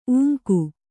♪ ūŋku